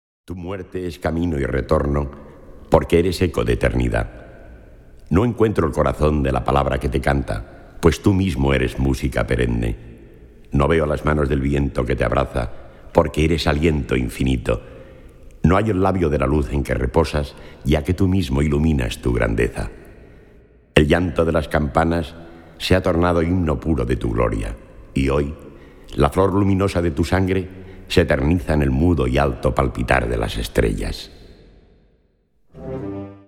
Categorie Harmonie/Fanfare/Brass-orkest
Bezetting Ha (harmonieorkest); SprS (verteller)